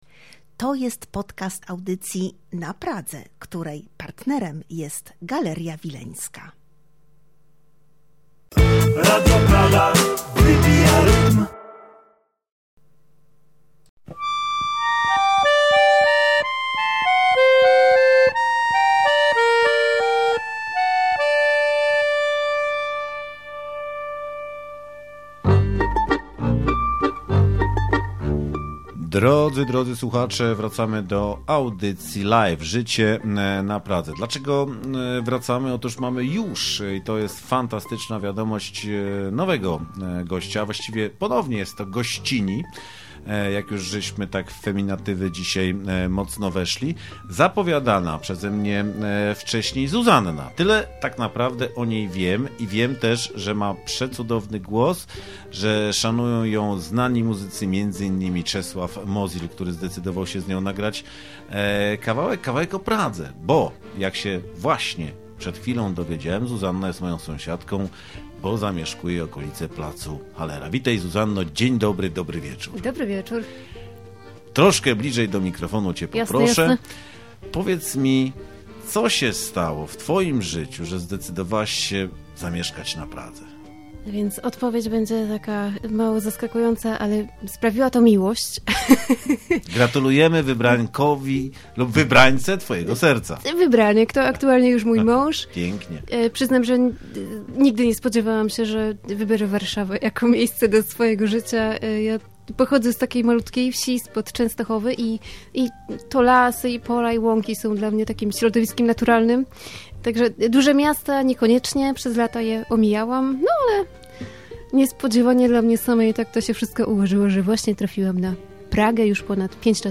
Rozmawiliśmy między innymi o muzyce, inspiracjach czy urokach Pragi. Oczywiści zagraliśmy też jej piosenki.